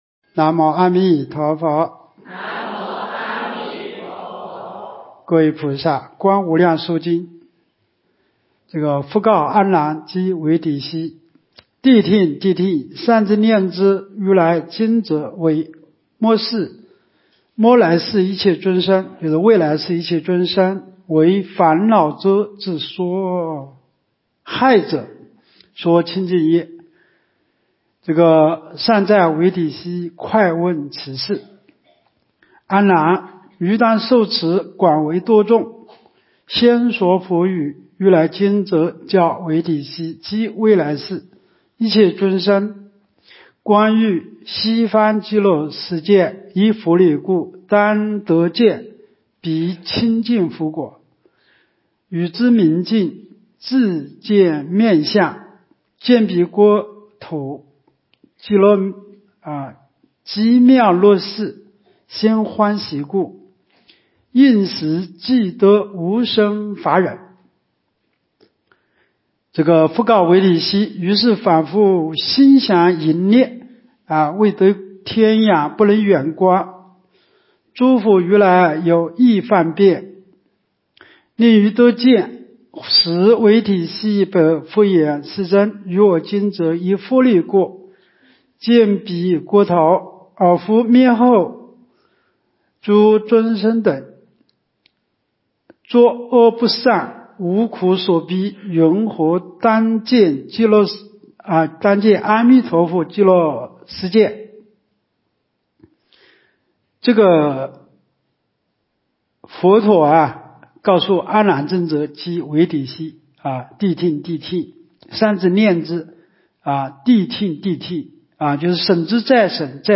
无量寿寺冬季极乐法会精进佛七开示（15）（观无量寿佛经）...